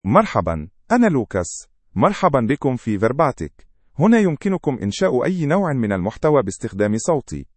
MaleArabic (Standard)
LucasMale Arabic AI voice
Lucas is a male AI voice for Arabic (Standard).
Voice sample
Male
Lucas delivers clear pronunciation with authentic Standard Arabic intonation, making your content sound professionally produced.